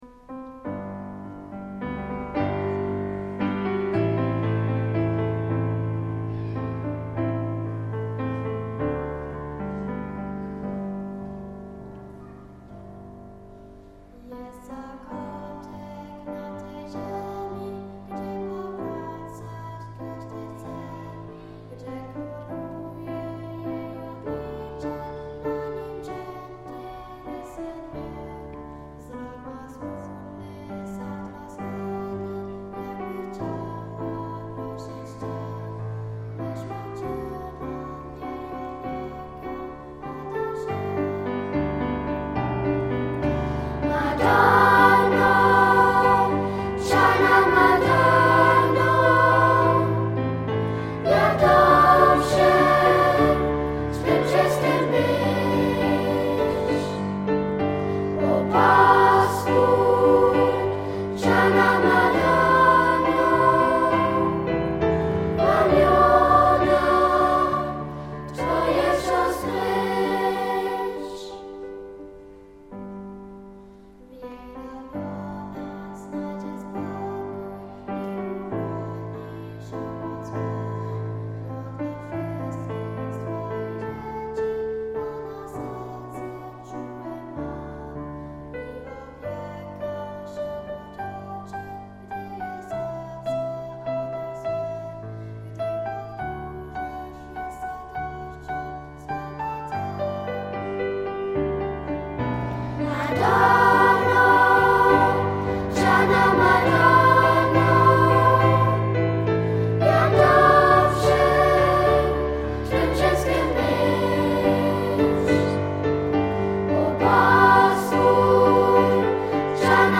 Chór z Bad Dürkheim śpiewał także po polsku
Niezwykłych przeżyć dostarczył nam chór dziecięcy "Pfälzer Weinkehlchen" z partnerskiego powiatu Bad Dürkheim, który gości w powiecie kluczborskim.
Obie wykonane bardzo poprawną polszczyzną, tak samo zresztą jak cały koncert i w Wołczynie i w kościele ewangelicko – augsburskim, spotkały się z entuzjastycznym przyjęciem.
Śpiewacy z chóru Pfälzer Weinkehlchen mają od 6 do 18 lat.
Pieśń "Czarna Madonna" w wykonaniu chóru (.mp3)